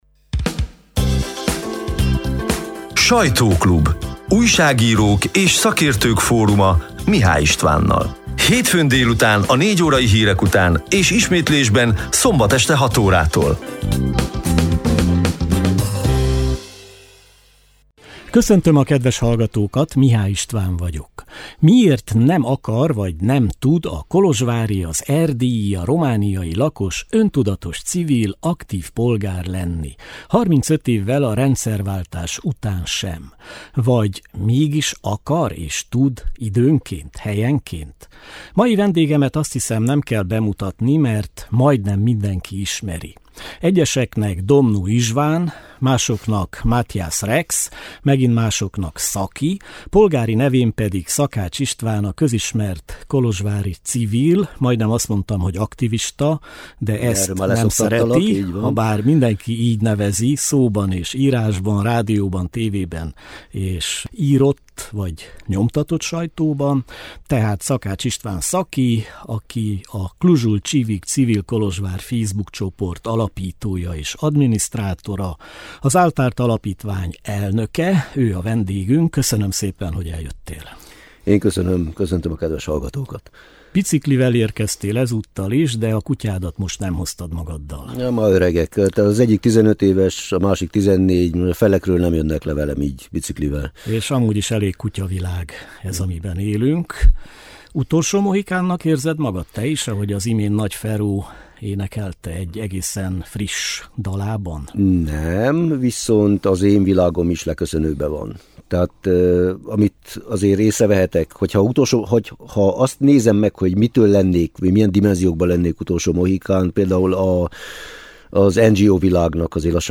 Érdemes meghallgatni a február 10-i, hétfő délutáni 55 perces élő műsor szerkesztett változatát.